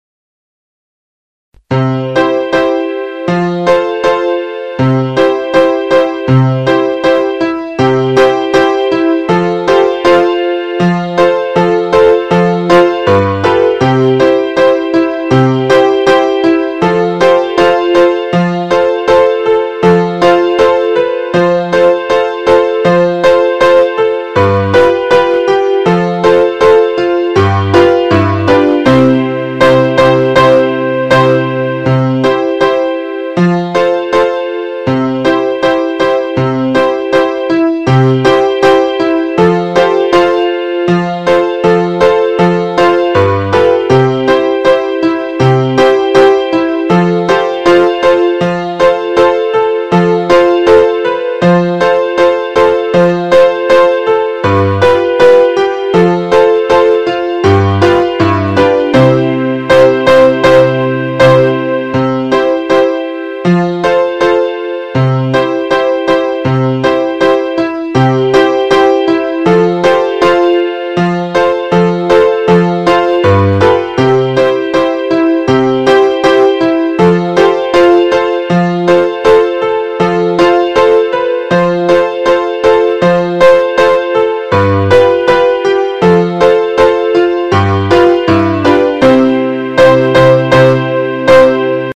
vi servirà  come base per cantare   o suonare